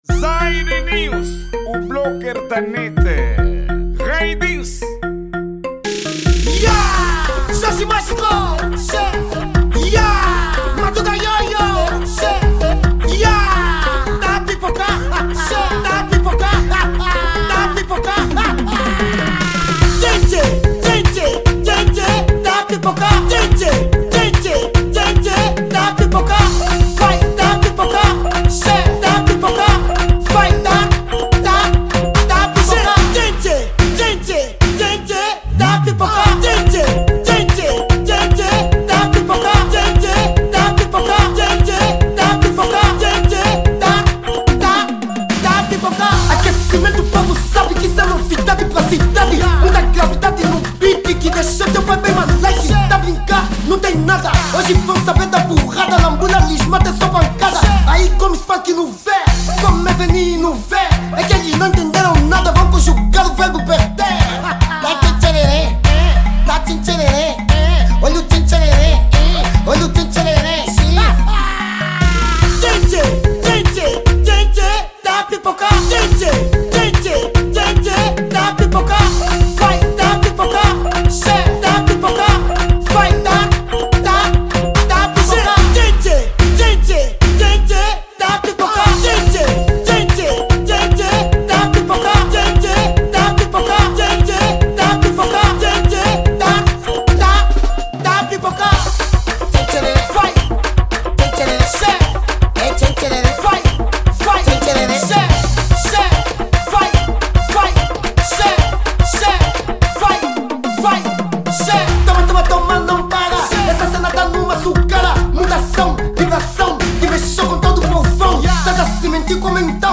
Kuduro